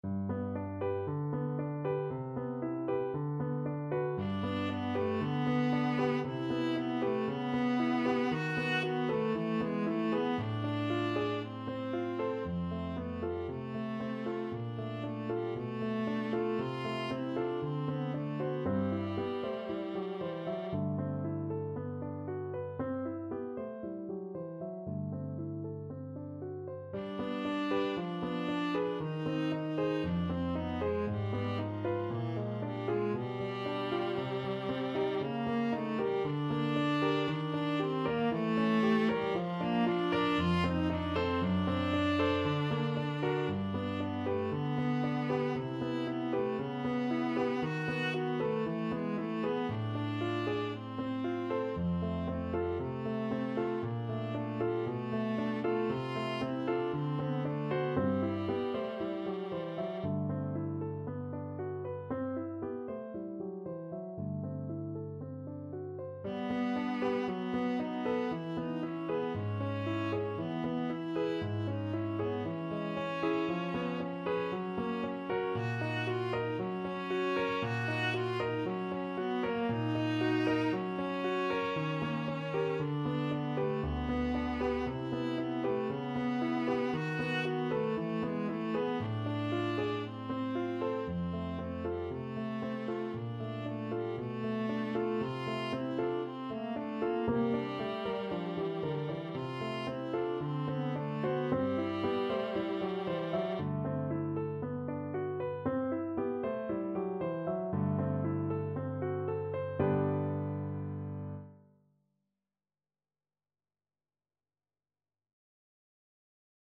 Chanson d'amour Viola version
G major (Sounding Pitch) (View more G major Music for Viola )
Allegro moderato =116 (View more music marked Allegro)
4/4 (View more 4/4 Music)
Viola  (View more Intermediate Viola Music)
Classical (View more Classical Viola Music)